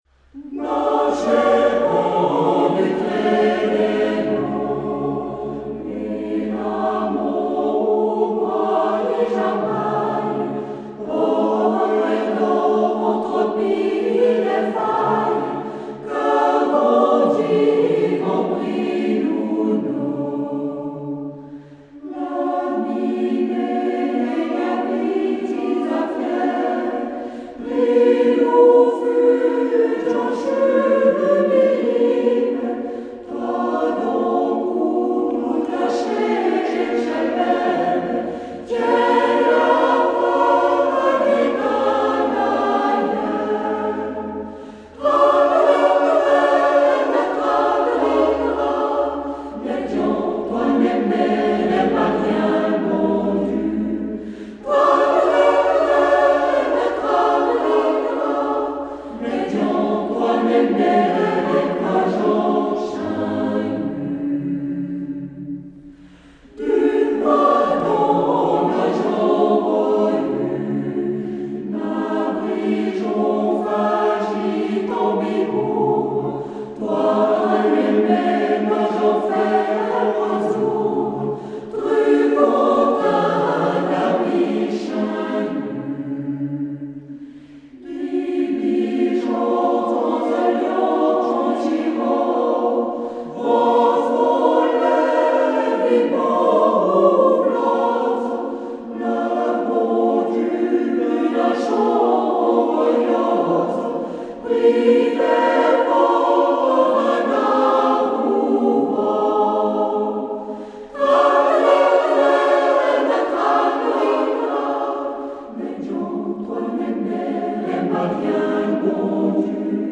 Pè Vè la miné interprété par le Groupe Choral Intyamon